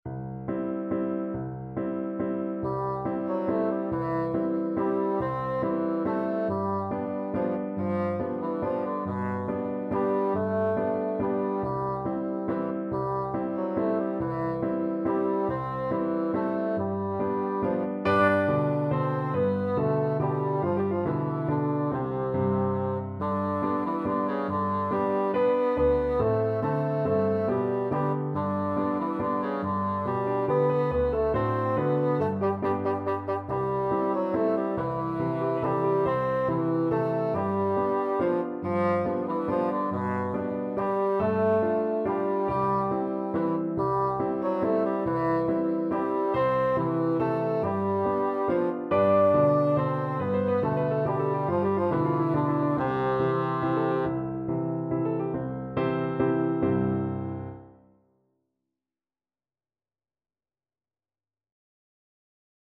Allegretto = c.140
3/4 (View more 3/4 Music)
B3-D5
Neapolitan Songs for Bassoon